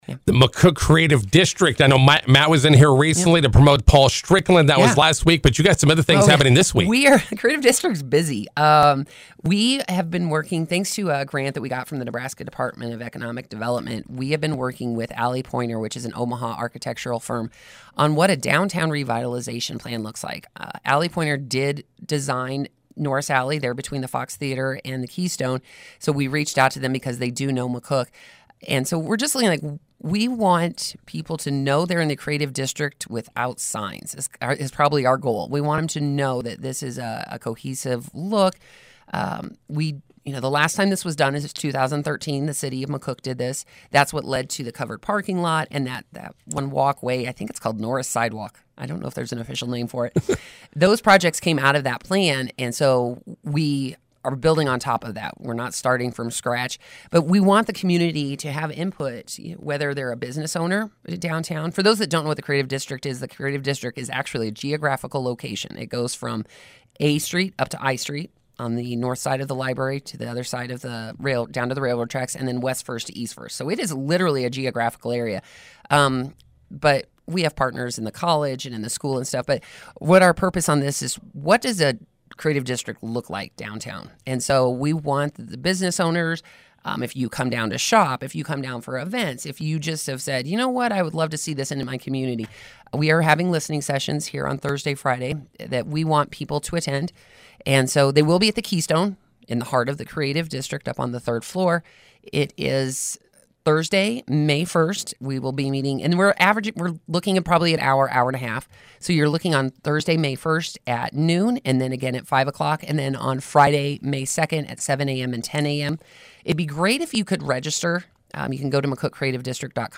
INTERVIEW: McCook Creative District hosting Downtown Listening Sessions this week.